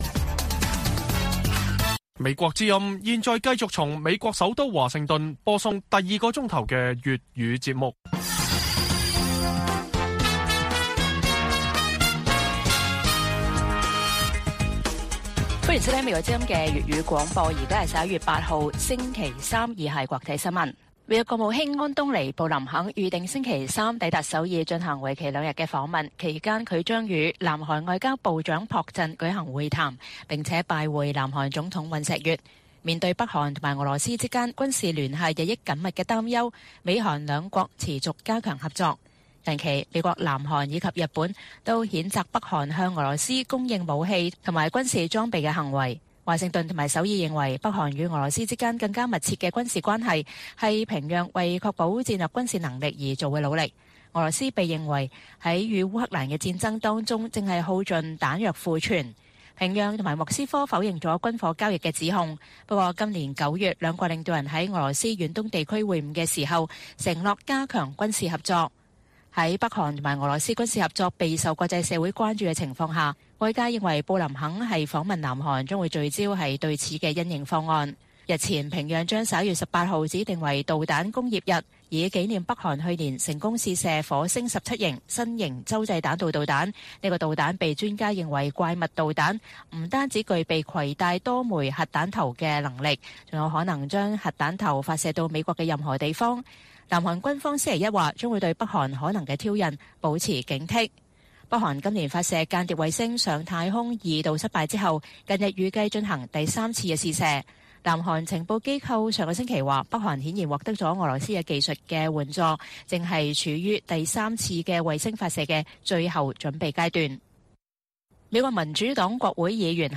粵語新聞 晚上10-11點: 隨著北韓俄國威脅增加 布林肯訪問南韓加強美韓合作